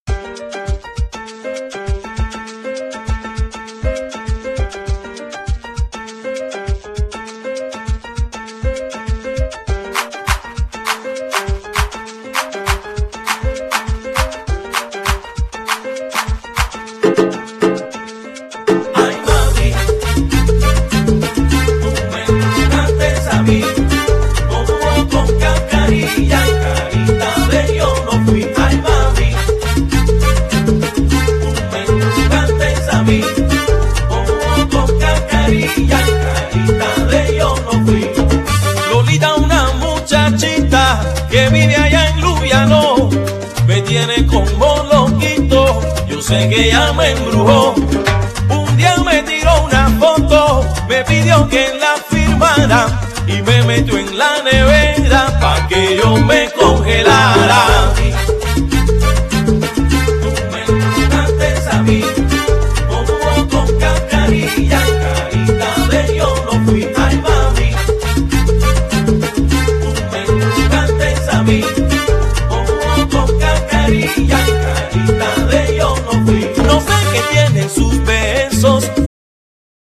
Genere : Pop latino
sfumature moderne di salsaton